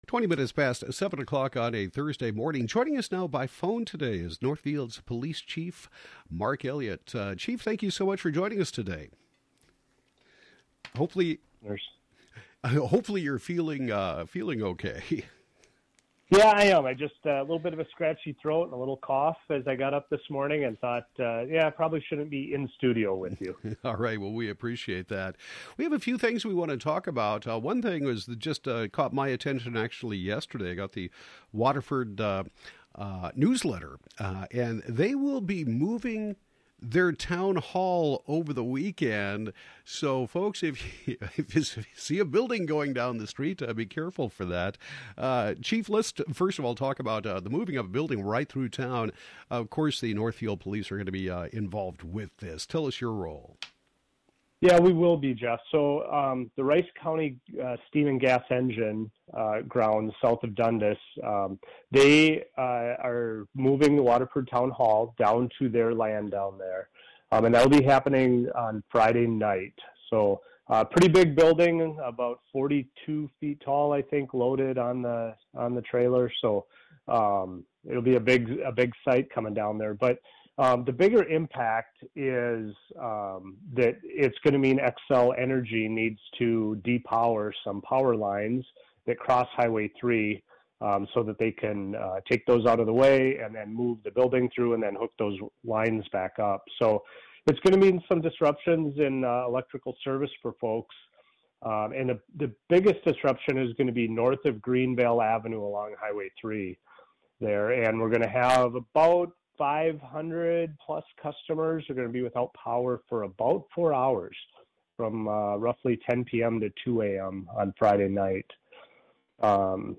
Northfield Police Chief Mark Elliott talks about a grant that is helping to fund mental health response and the assistance that can be provided by the police department.